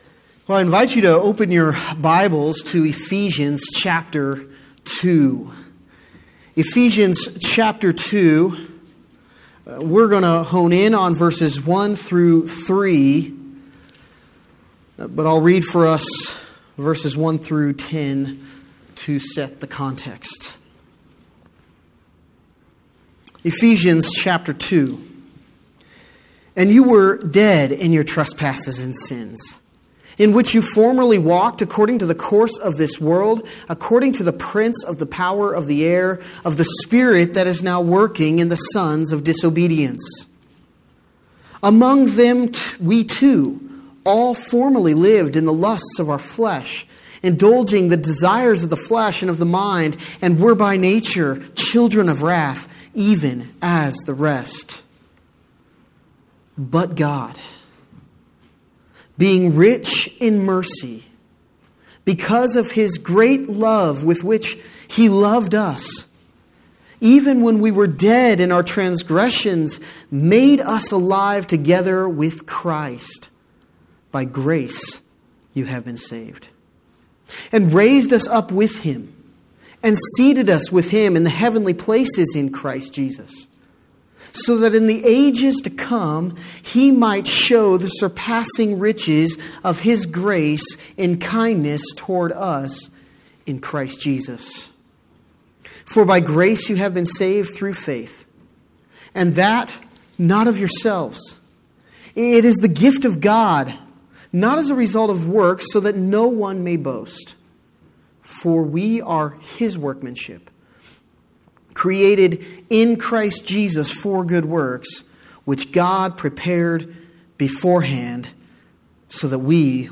The message on Sunday